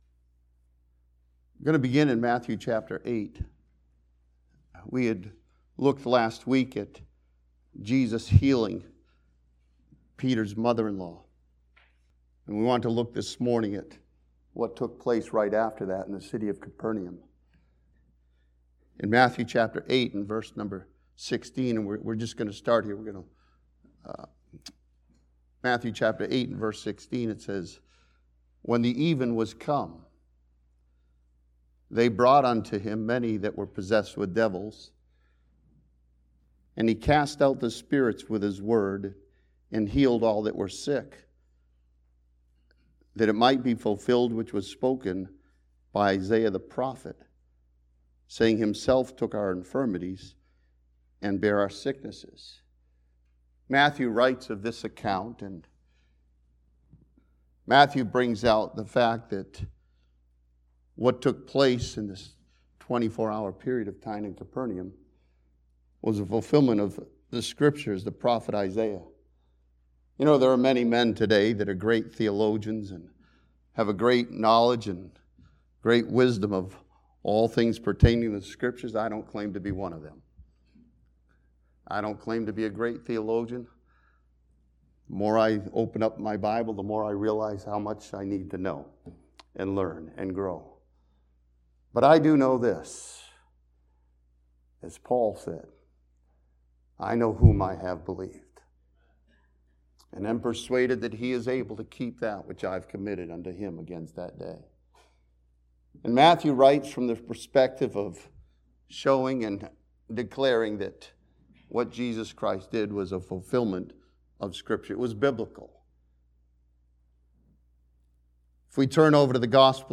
This sermon from Matthew chapter 8 studies the compassionate nature of Jesus Christ as He heals the multitude.